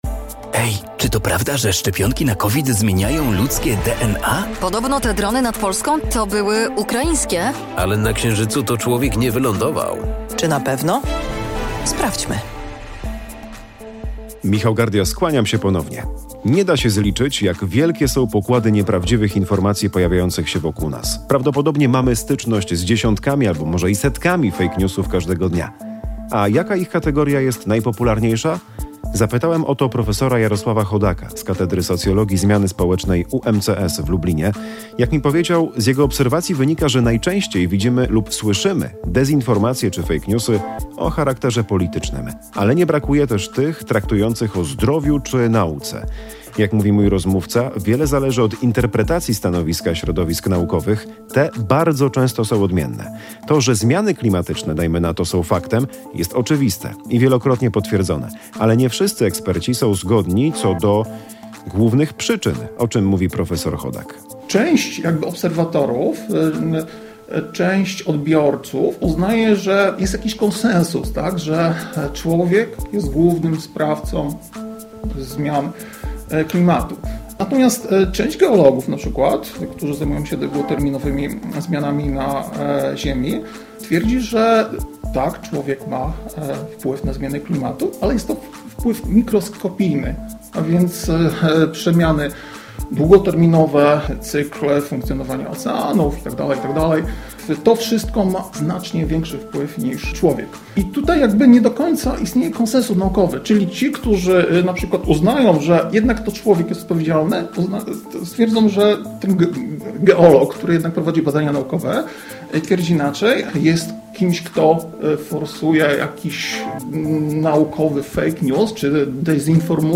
We wstępie każdego odcinka „Czy na pewno?” słyszą Państwo trzy pytania. Lektorzy improwizują sytuację, w której dociekliwy i sceptyczny wobec oficjalnych przekazów człowiek chce dowiedzieć się prawdy.